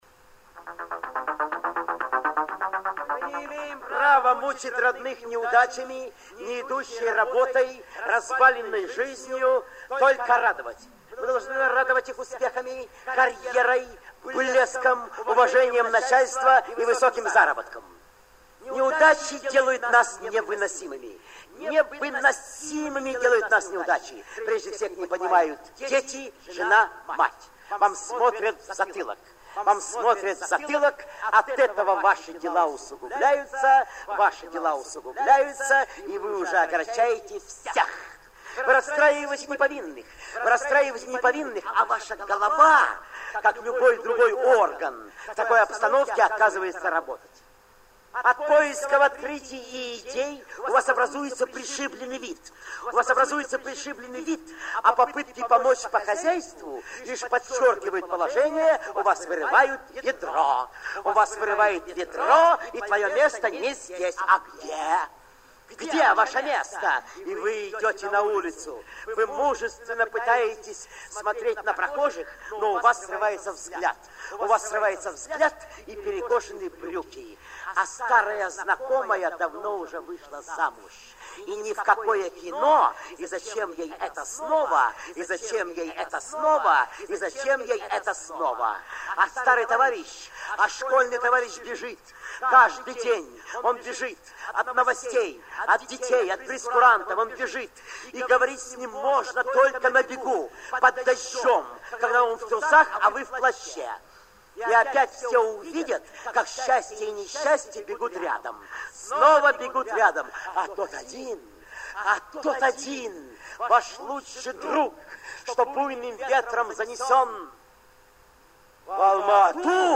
Продолжение редких записей миниатюр в исполнении Виктора Ильченко и Романа Карцева. 02 - В.Ильченко-Р.Карцев - Жду звонка